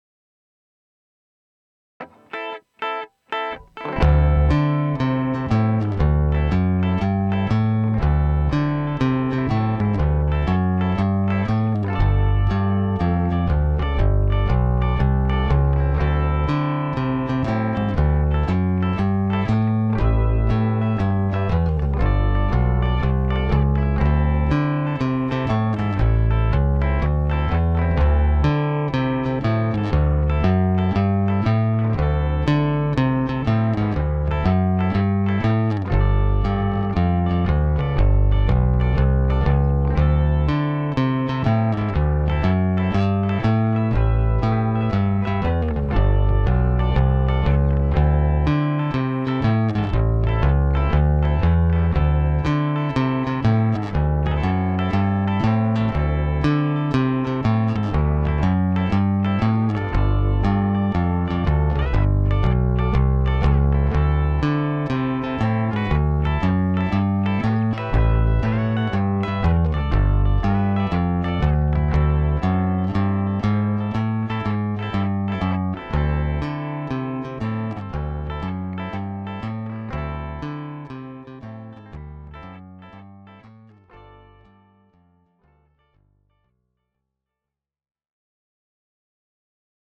Here a one minute mp3 of 3qty DI Bass tracks...PBAss> Interface DI, PBass into ISA430, Pbass into Aphex207.....no fx, no eq no comp. I dont hear much of a difference.